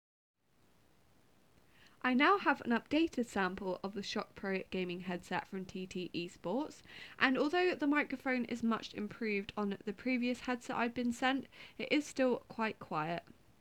• Mic: Omni-directional
• Noise cancelling Mic: No
The microphone on this headset is much better than our original sample and is now usable, although it is still slightly quieter than I would like. However the clarity is pretty impressive for such a cheap headset.